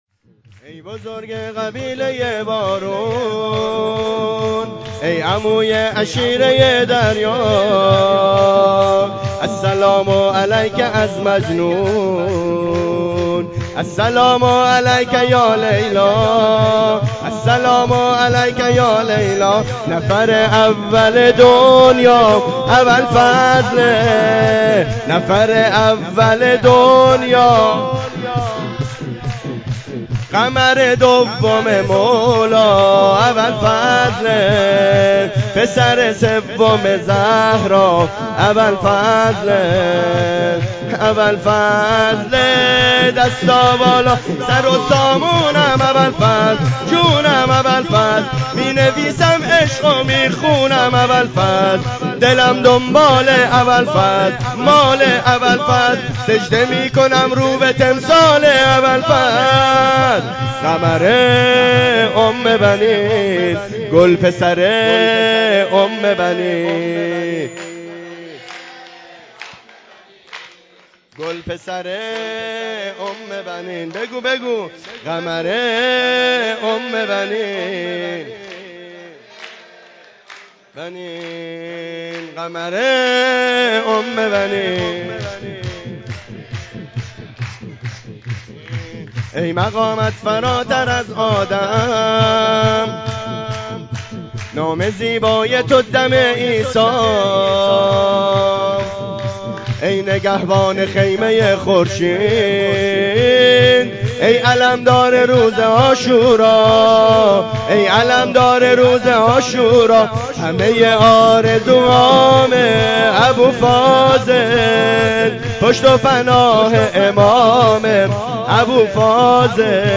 ویژه برنامه جشن بزرگ اعیاد شعبانیه و میلاد انوار کربلا1403